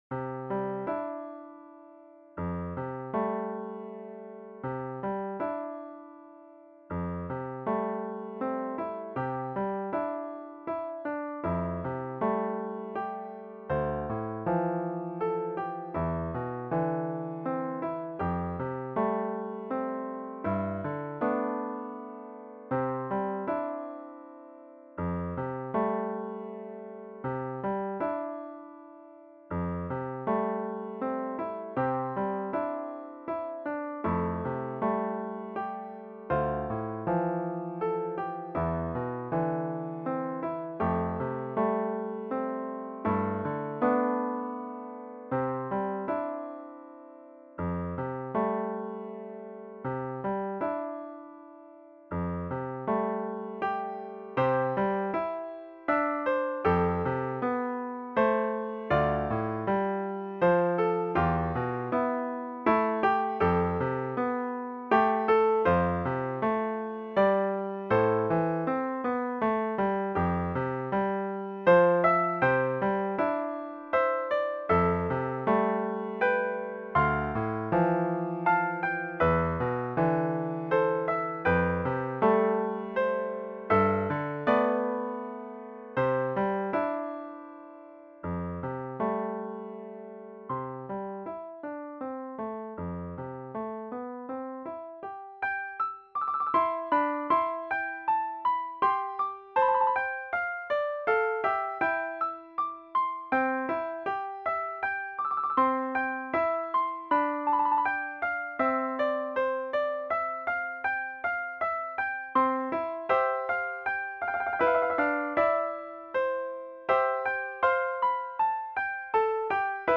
Piano Solo
Just reading the title of this beloved folk hymn brings comfort to the soul. In this peaceful piano solo setting, the melody is simple without complicated harmonies. The left hand plays a lilting rhythm throughout the first verse. In the second verse the left hand takes over the melody while the right hand plays a moving obligato with a few optional trills. The second verse continues with lush chords in every measure, the right hand taking the melody, continuing on to the end of the piece by revisiting the style of the first verse.